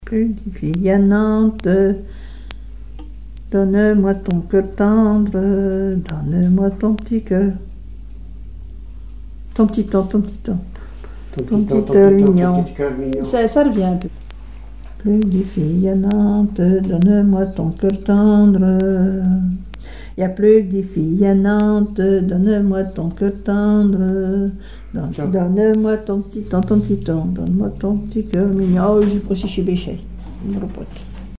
Mémoires et Patrimoines vivants - RaddO est une base de données d'archives iconographiques et sonores.
gestuel : à marcher
Genre énumérative
Répertoire de chansons traditionnelles et populaires
Pièce musicale inédite